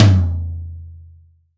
Index of /90_sSampleCDs/AKAI S6000 CD-ROM - Volume 3/Drum_Kit/DRY_KIT1
L-TOM15C-1-S.WAV